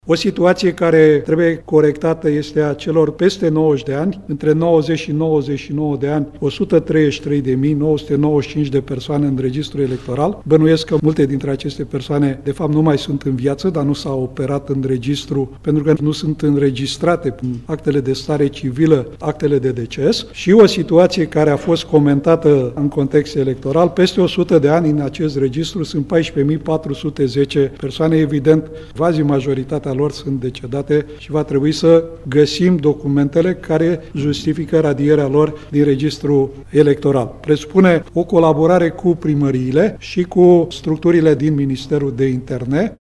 Adrian Țuțuianu a ținut astăzi o conferință de presă în care a prezentat o primă analiză realizată la Autoritatea Electorală Permanentă, a cărei conducere a preluat-o după demiterea lui Toni Greblă. Adrian Țuțuianu spune că datele din Registrul Electoral trebuie actualizate astfel încât acestea să nu mai conțină și numele unor persoane decedate.
Președintele Autorității Electorale Permanente, Adrian Țuțuianu: „Între 90 și 99 de ani sunt 133.995 de persoane în Registrul Electoral. Bănuiesc că multe dintre aceste persoane nu mai sunt în viață”